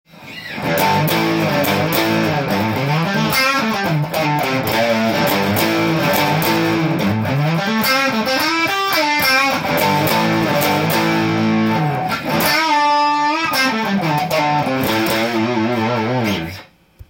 シングルコイルで歪ませて弾いてみました
ロック感満載なシングルコイルでの歪んだ音でした。
しかし、シングルコイルで弾くと少しレトロな昔の感じさせる雰囲気です。